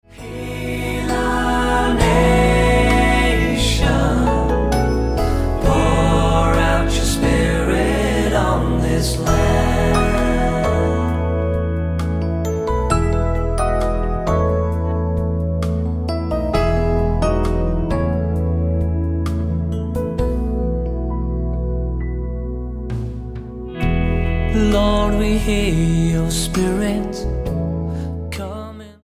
STYLE: MOR / Soft Pop
Pleasant praise and worship.